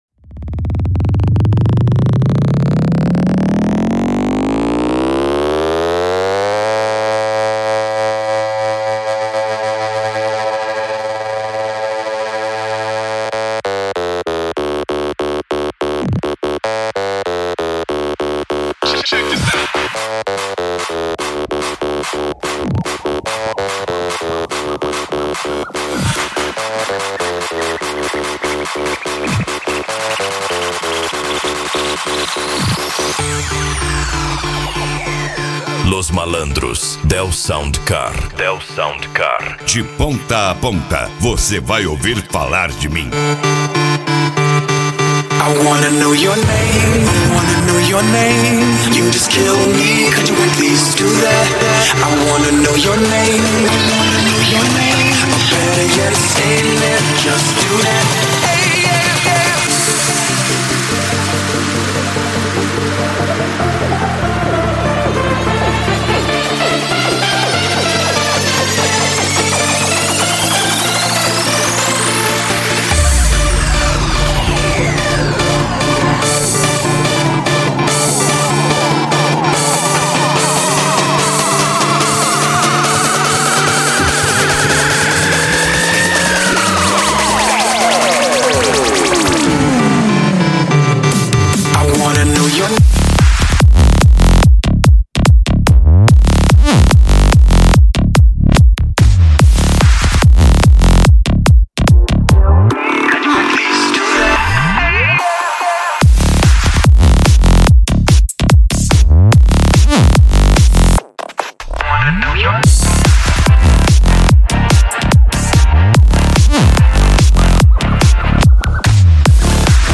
Bass
Eletronica
Psy Trance